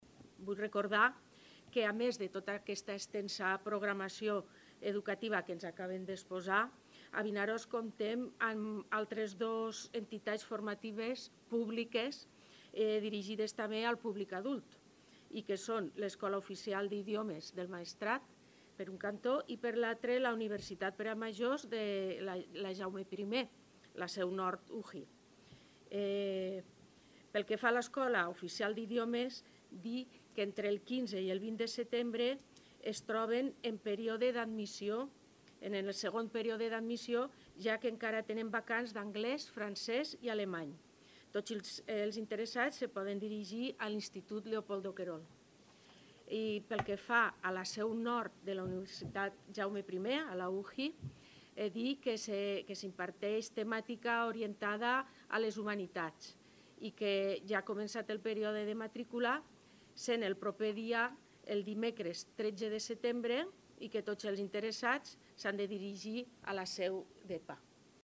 María Miralles, regidora d’educació de Vinaròs